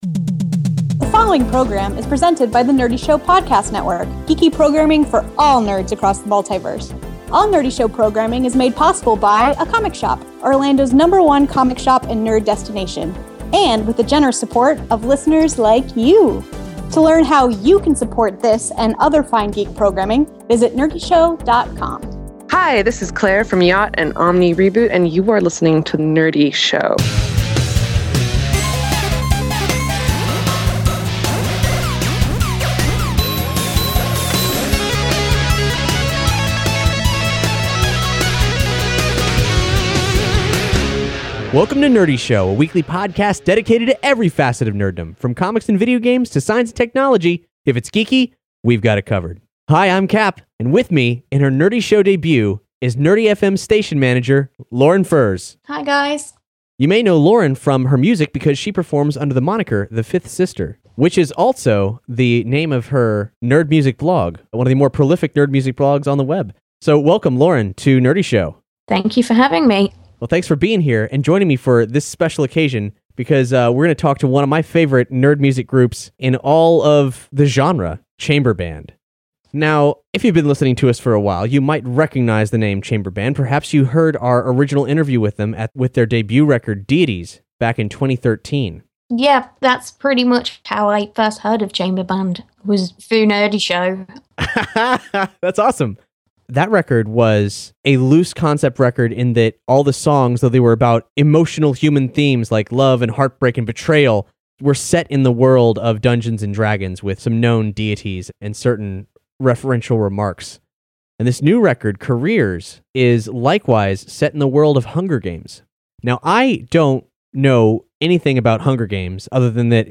Episode 237 :: Interview: Chamber Band Brave The Hunger Games